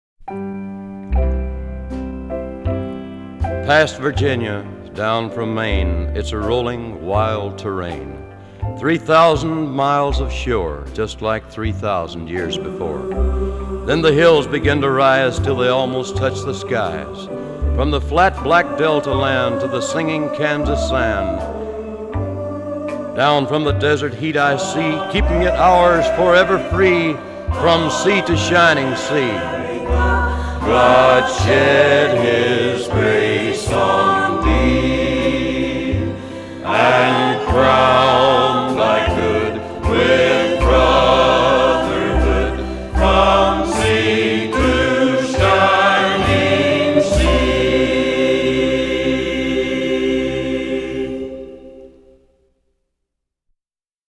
Жанр: Folk, World, & Country